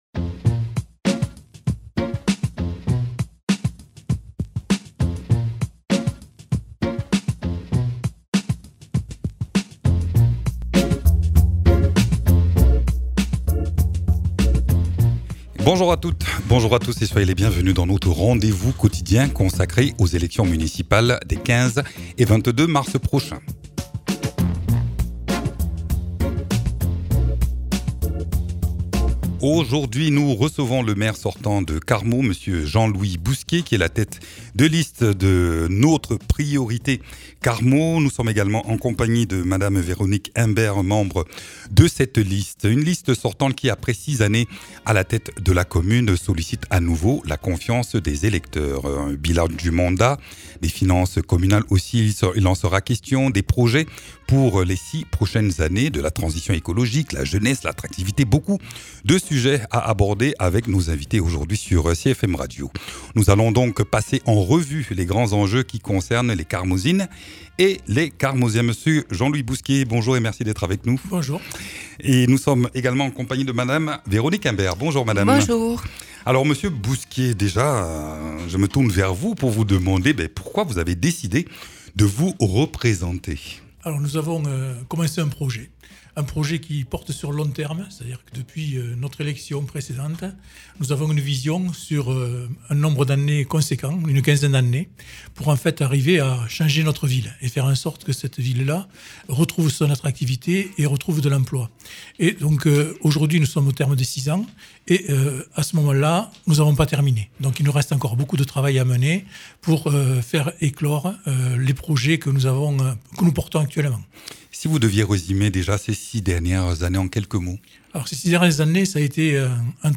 Interviews
Invité(s) : Jean-Louis Bousquet et Véronique Imbert, élus sortants de la ville de Carmaux.